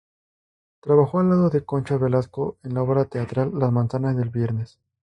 Read more Friday Frequency B1 Hyphenated as vier‧nes Pronounced as (IPA) /ˈbjeɾnes/ Etymology Inherited from Latin diēs Veneris In summary Inherited from Latin (diēs) Veneris.